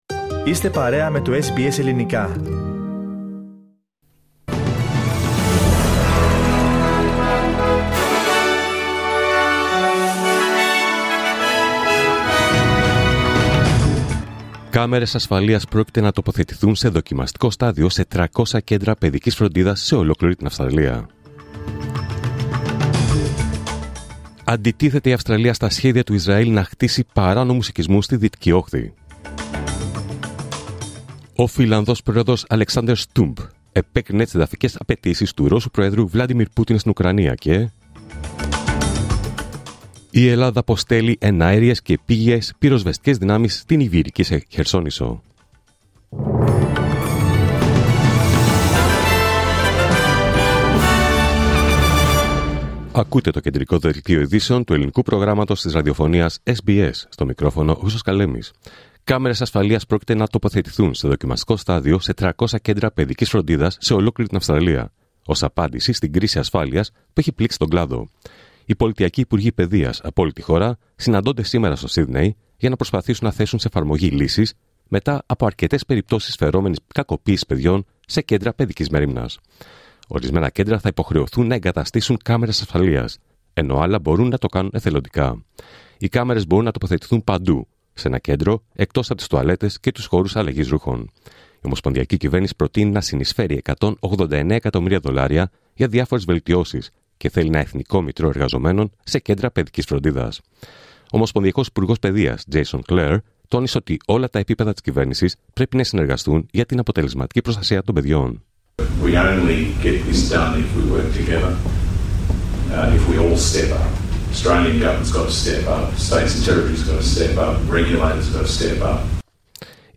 Δελτίο Ειδήσεων Παρασκευή 22 Αυγούστου 2025